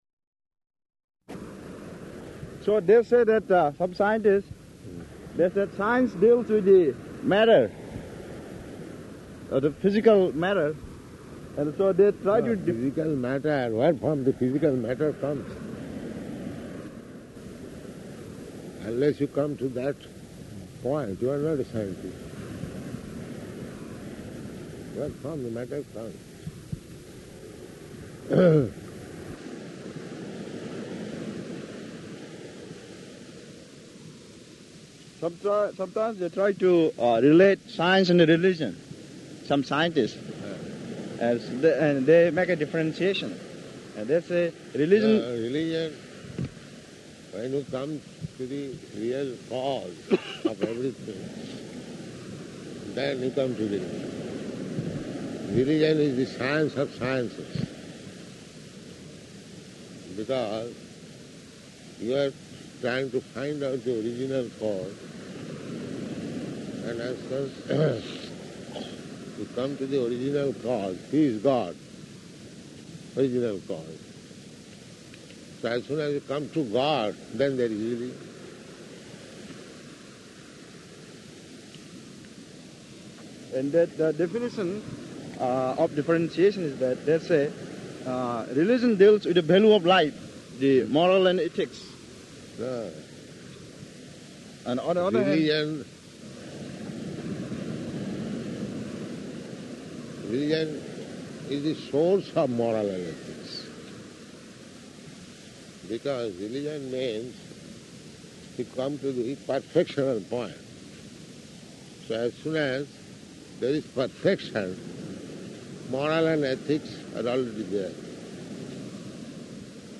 Type: Walk
Location: Los Angeles